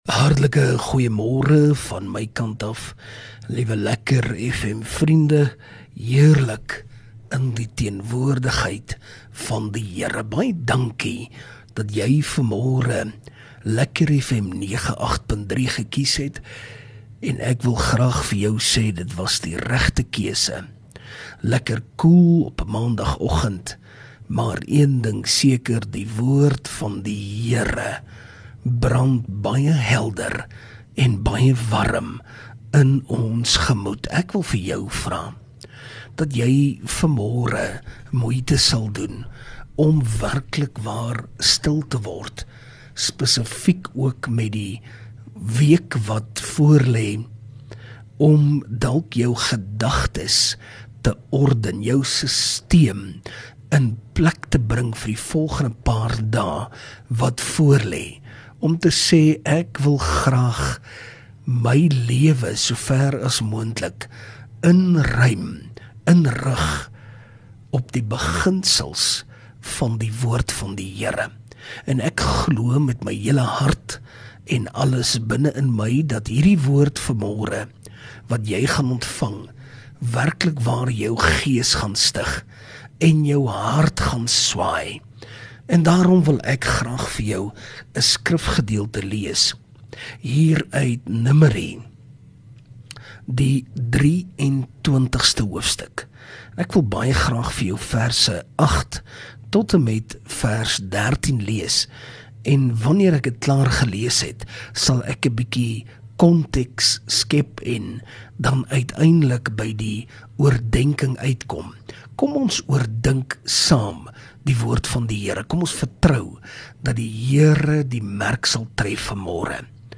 View Promo Continue Install LEKKER FM | Oggendoordenkings